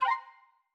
confirm_style_4_001.wav